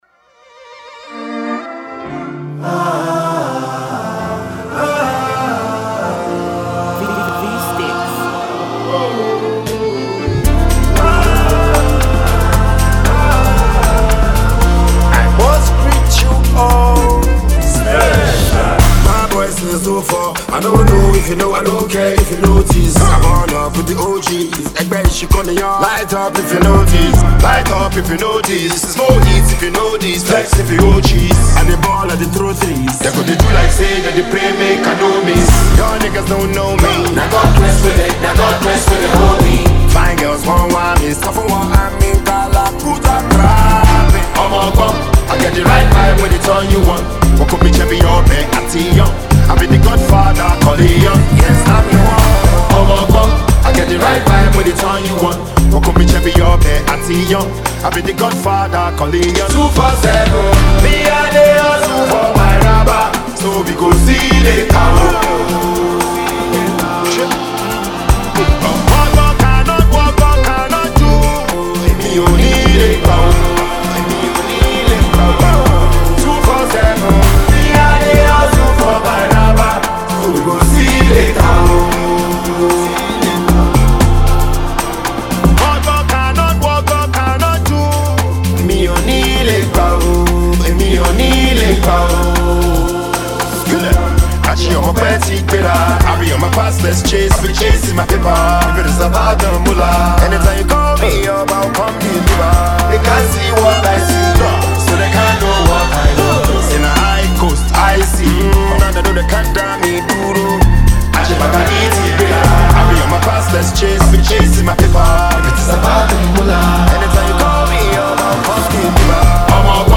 Enjoy this amapiano-gingered Naija Afrobeats.